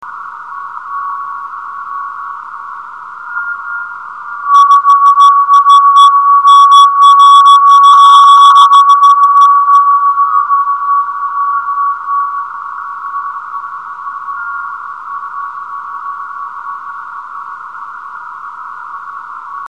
suono caratteristico di una meteora iperdensa oscillante (mp3/310Kb)
oscill.mp3